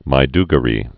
(mī-dg-rē)